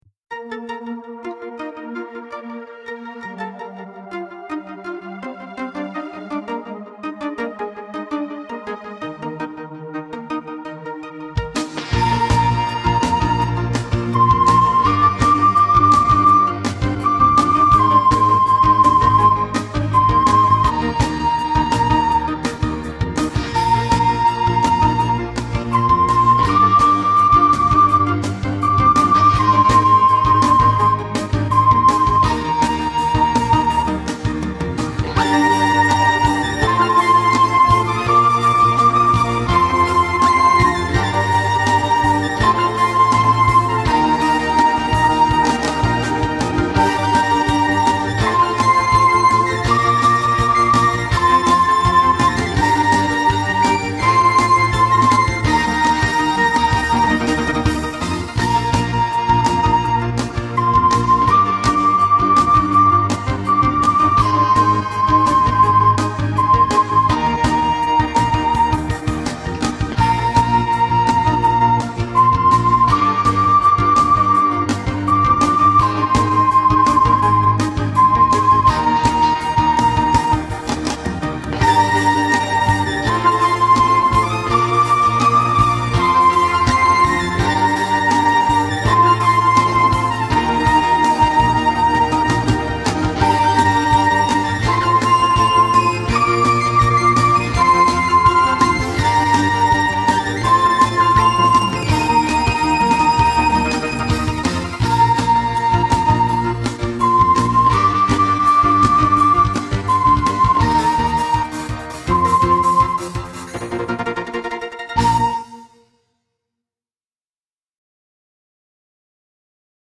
Three Star Cafe (fast).mp3